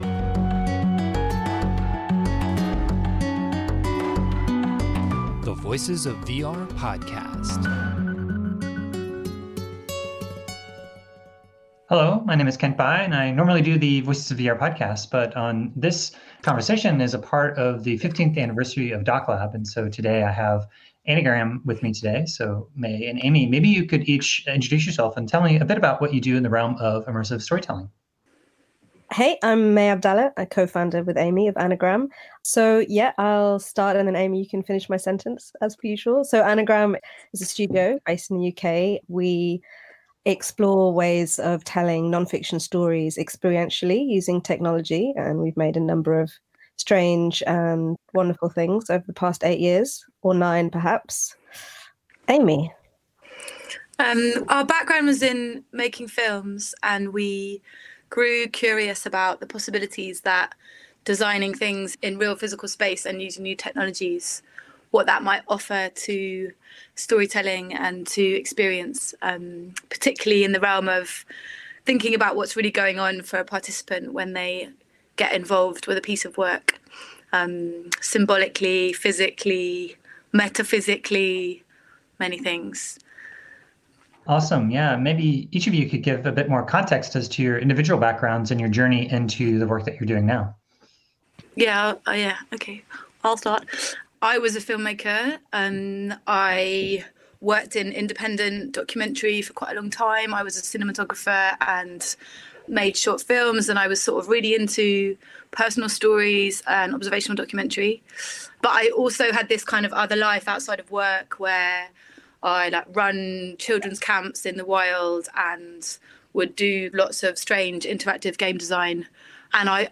This conversation was recorded on Friday, December 3, 2021 as a part of a collaboration with IDFA’s DocLab to celebrate their 15th year anniversary.